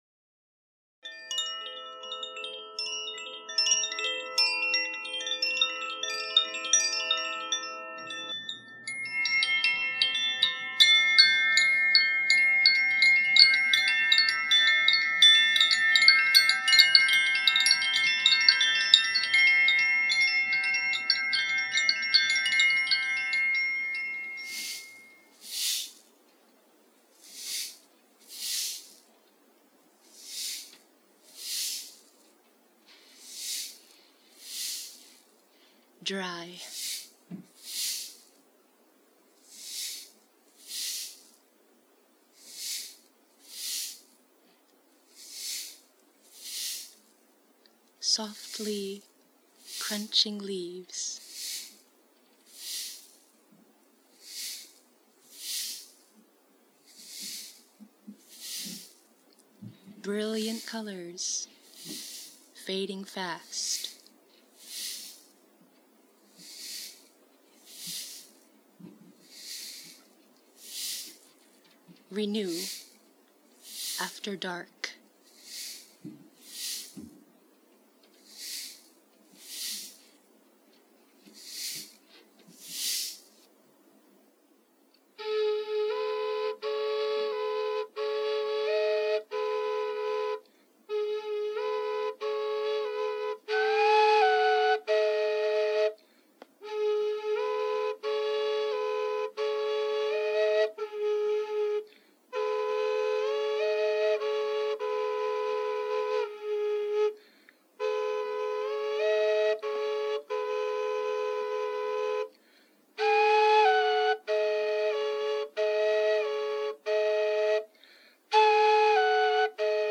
Flute: Nova Double Flute in A Minor + Koshi Aqua Chime, Ocean Drum, Redwood Claves, Hourglass Shaker, Zaphir Sufi Chime
ee9d6-dry-flutehaiku.mp3